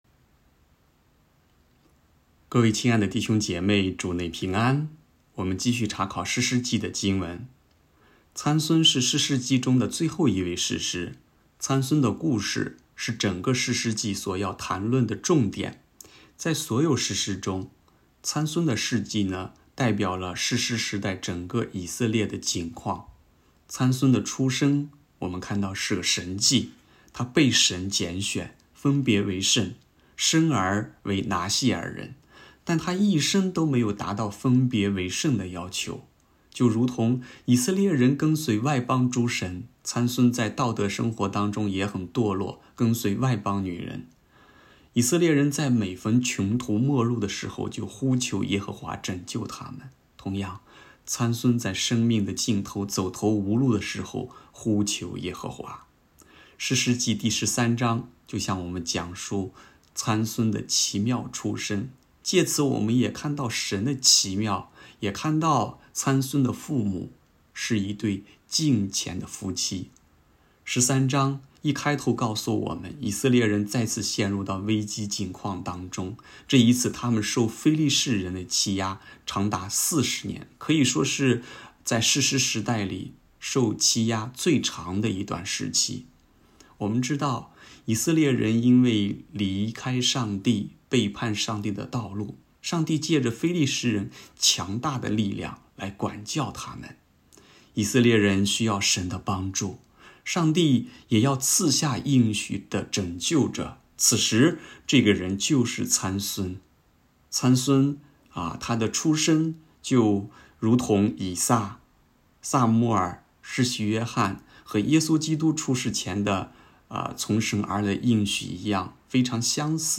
士师参孙》 证道